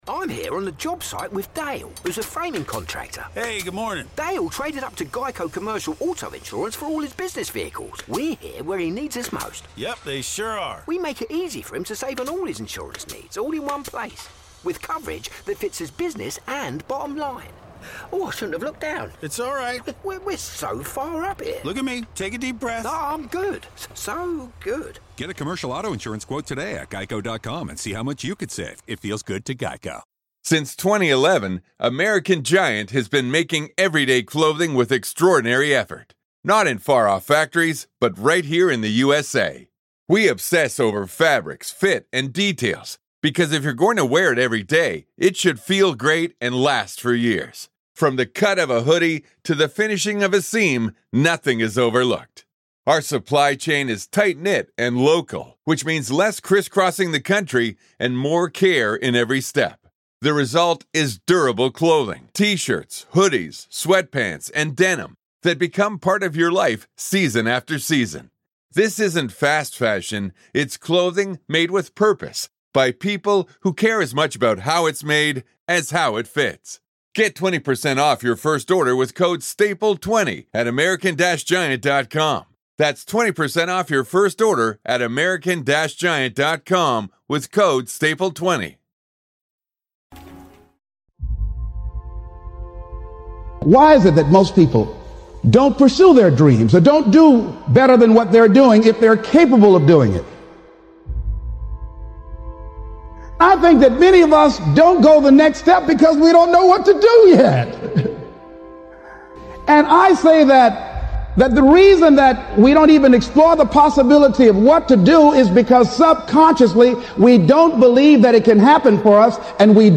The voice you hear in this Episode is that of Les Brown.